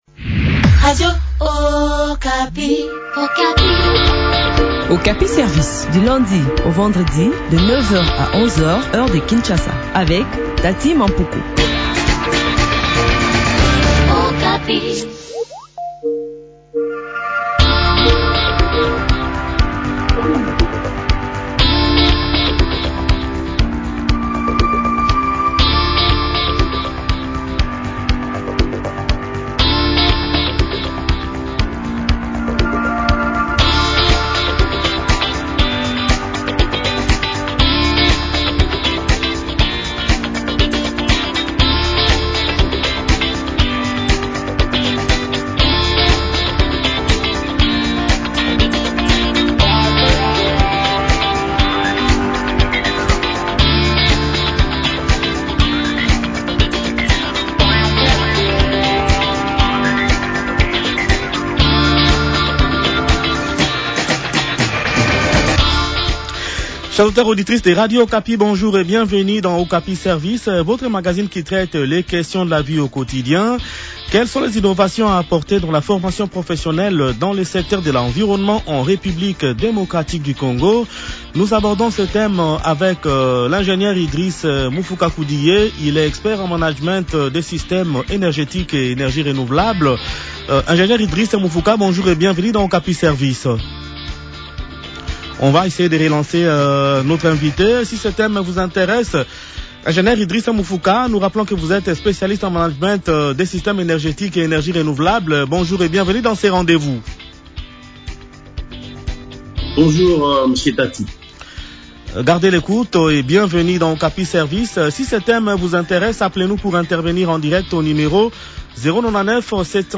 expert en management des systèmes énergétiques et énergies renouvelables.